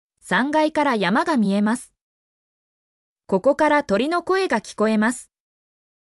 mp3-output-ttsfreedotcom-15_tmOnfvtk.mp3